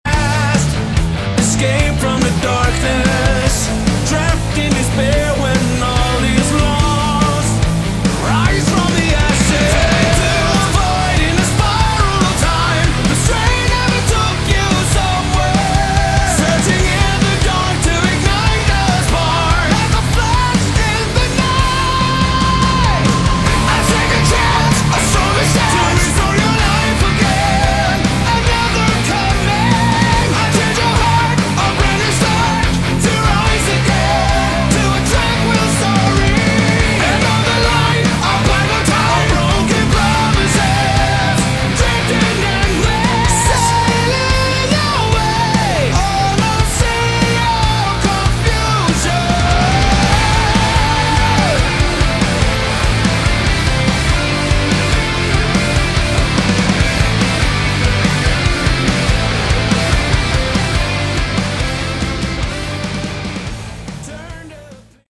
Category: Melodic Metal
vocals
guitars, bass, keys, drums